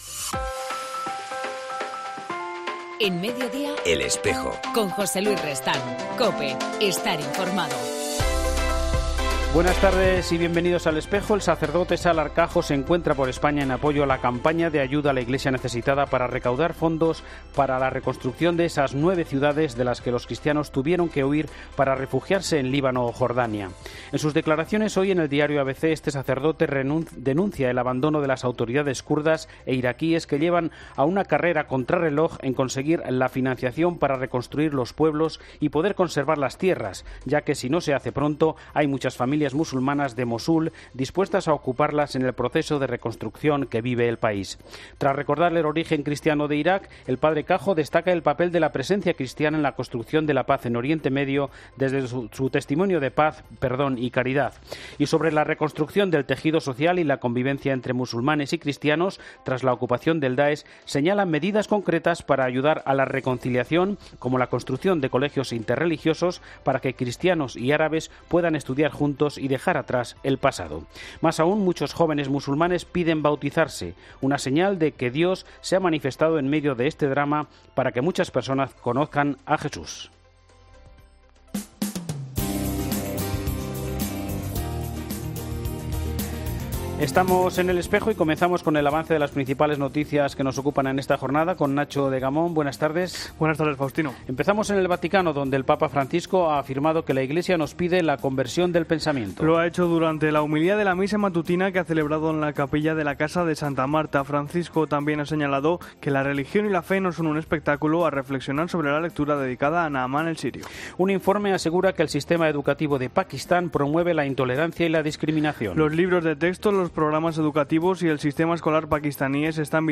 En El Espejo del 5 de marzo hablamos con el sacerdote